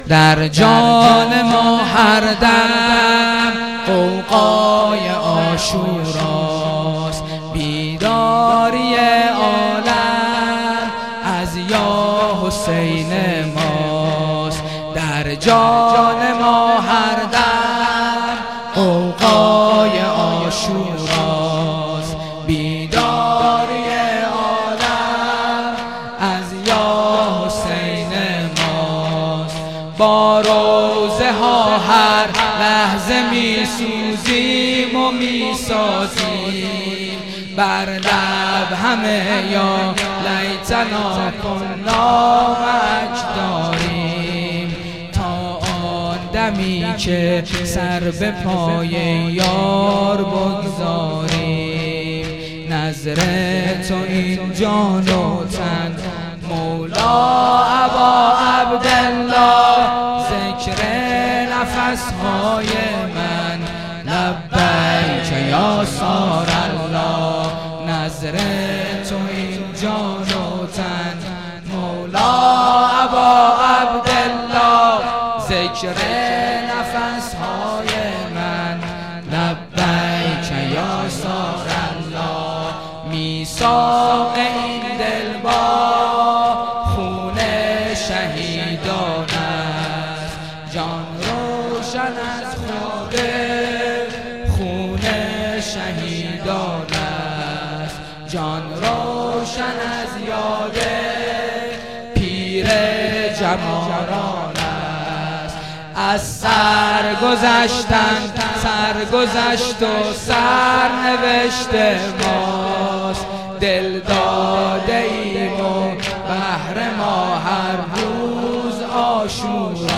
خیمه گاه - هیئت قتیل العبرات - سرود همگانی